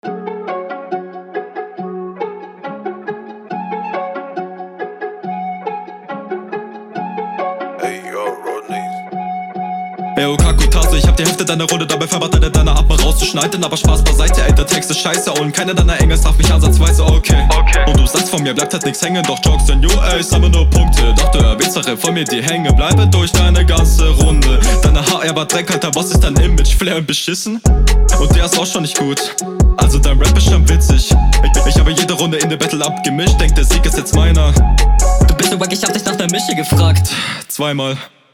beste runde im battle. flow weiss hier zu gefallen. was war mit der quali in …